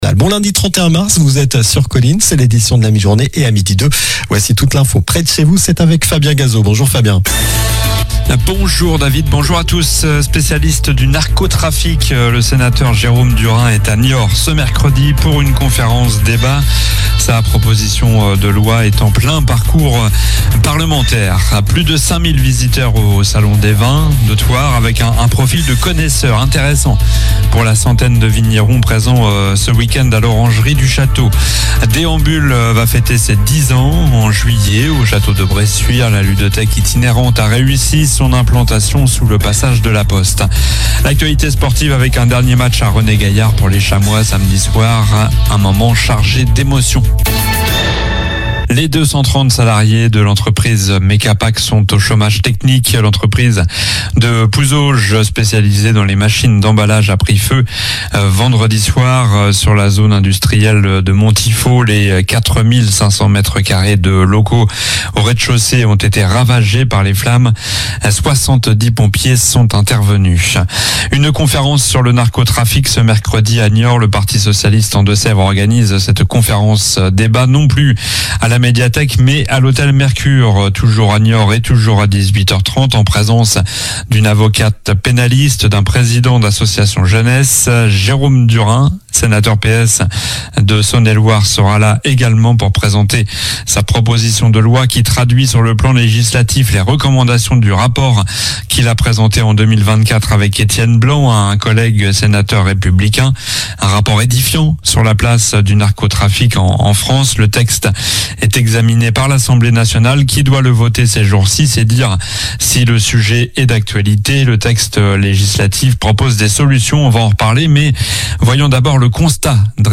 Journal du lundi 31 mars (midi)